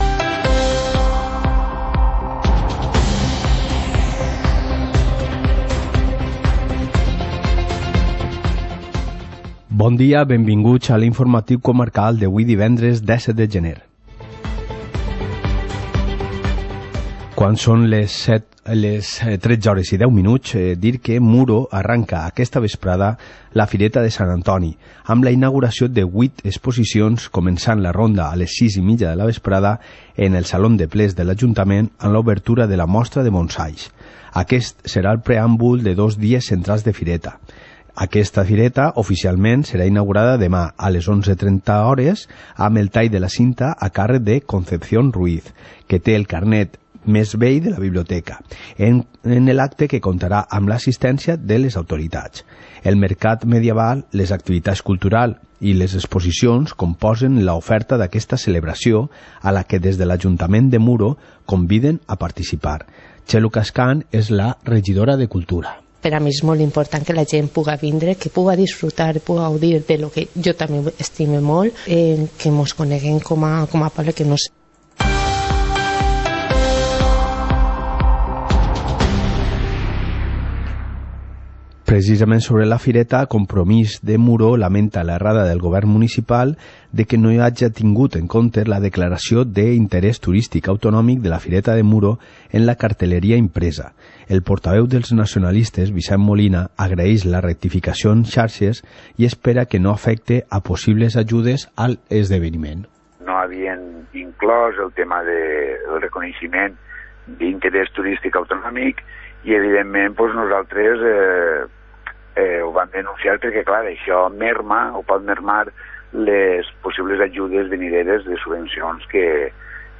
Informativo comarcal - viernes, 17 de enero de 2020